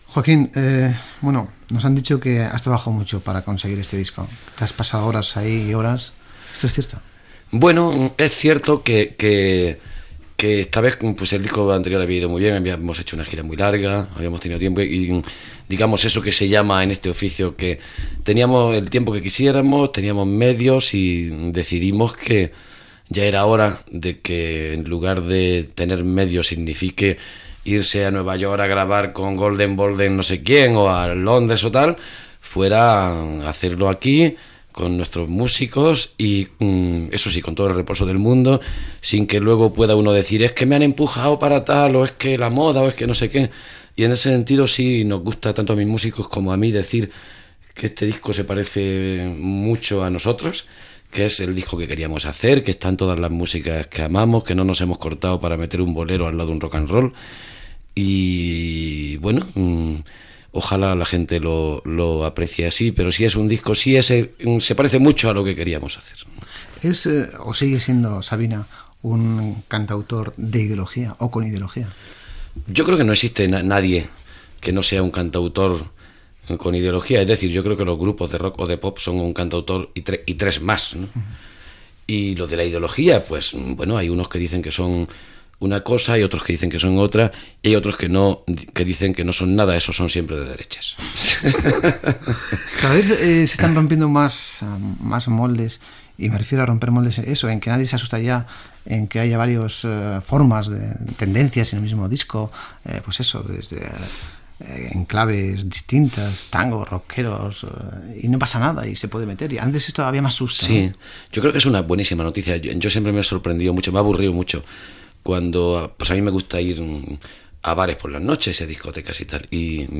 Entrevista al cantant Joaquín Sabina que presenta el seu novè disc, "Esta boca es mía"